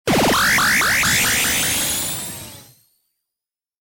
• インパクト予告（炎号）